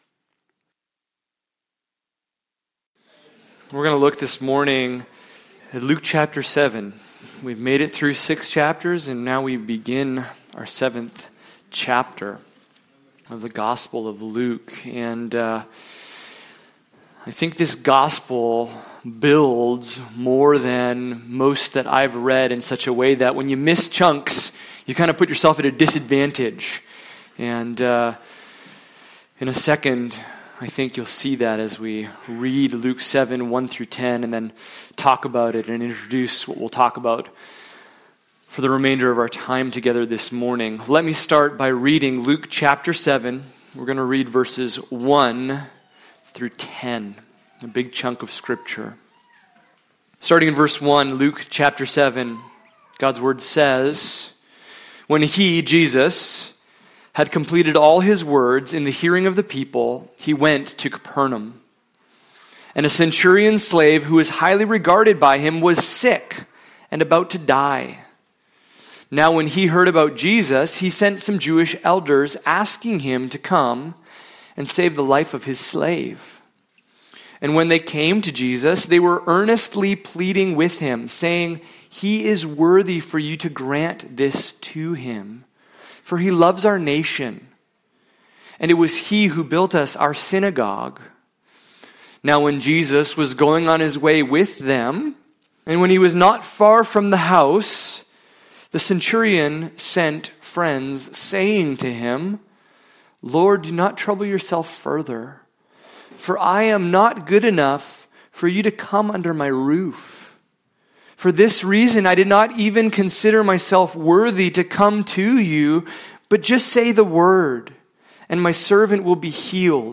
Luke Passage: Luke 7:1-10 Service Type: Sunday Morning « The Kingdom Paradox